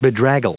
Prononciation du mot bedraggle en anglais (fichier audio)
Prononciation du mot : bedraggle